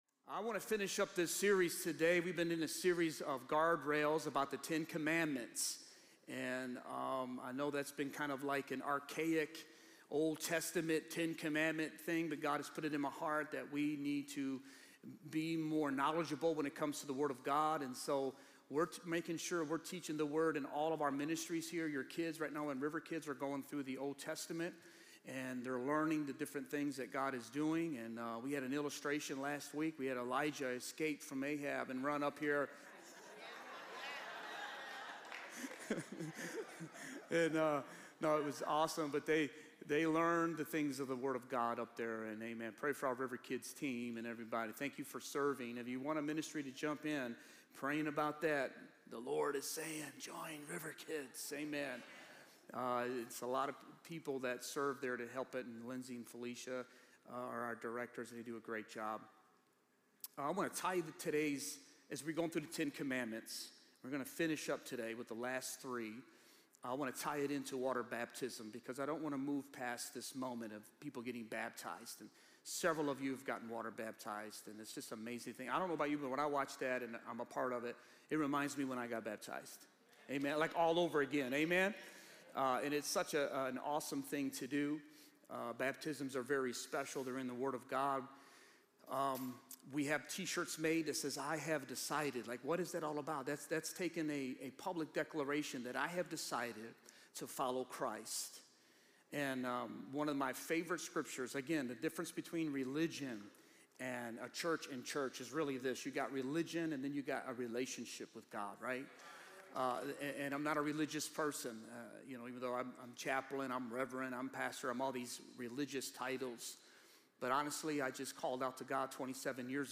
Sermons | River of Life Church